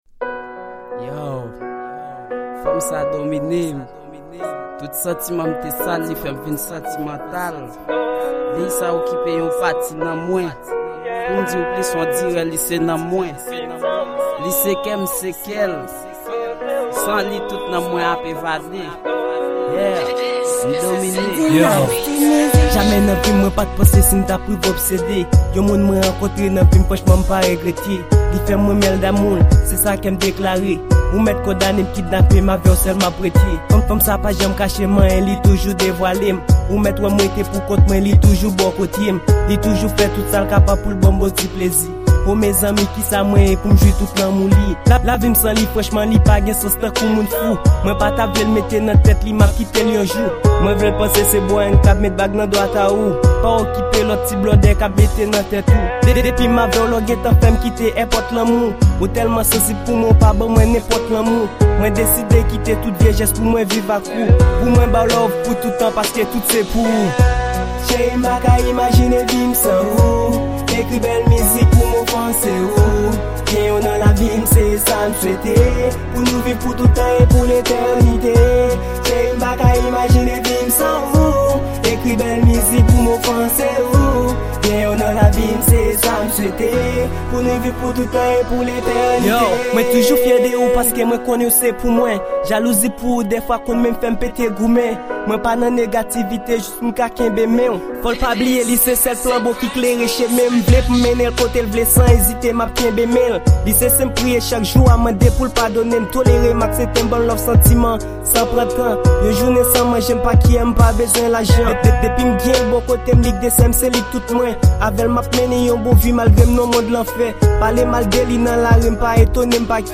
Genre: Rap